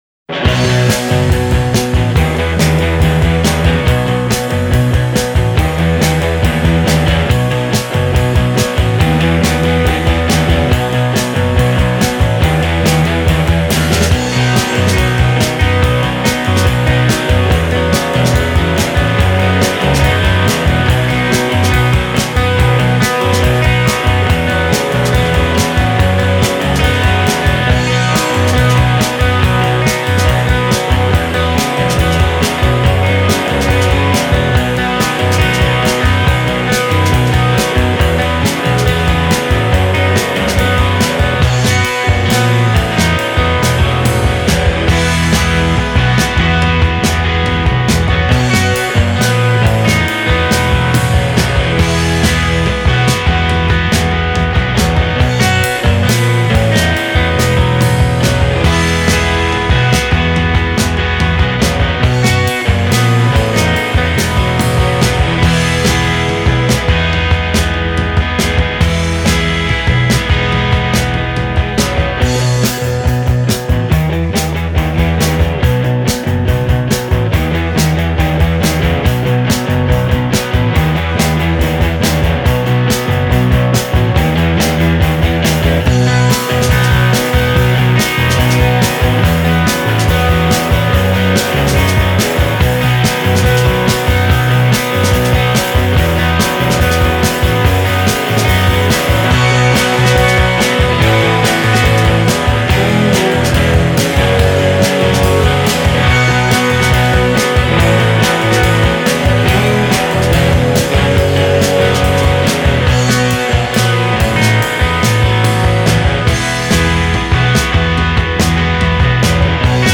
guitar
drums, percussion